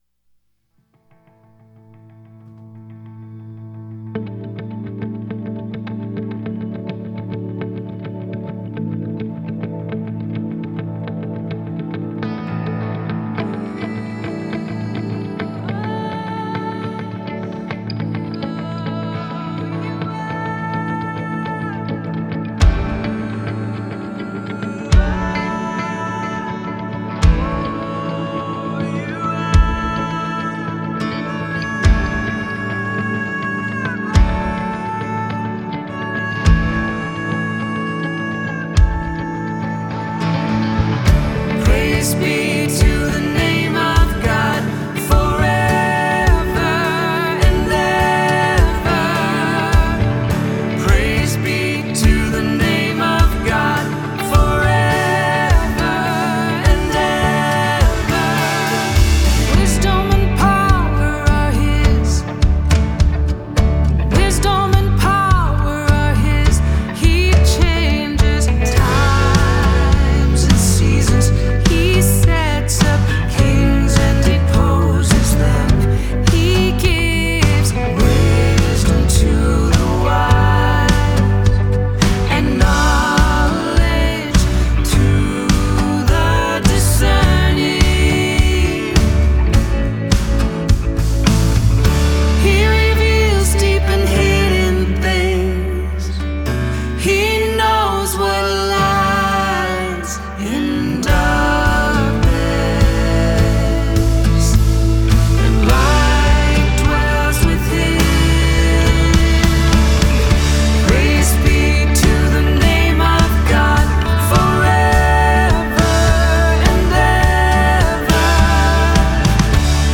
a husband-and-wife Christian music duo